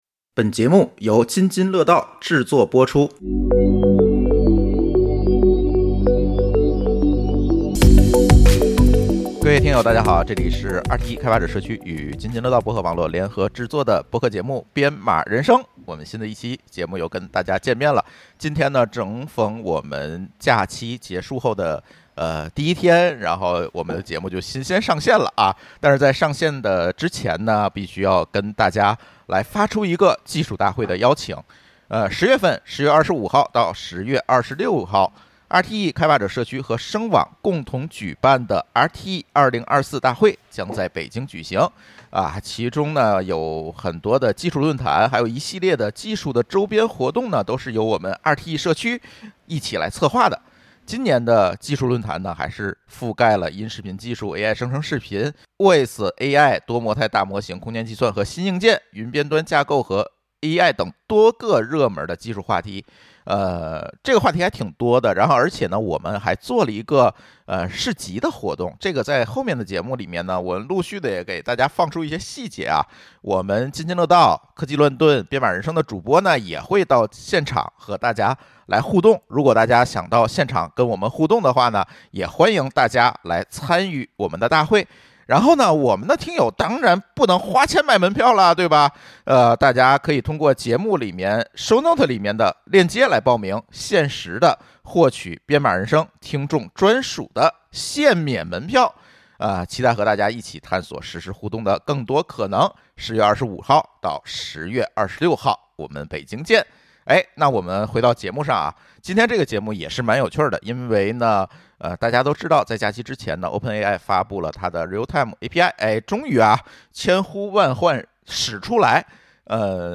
【本期嘉宾和主播】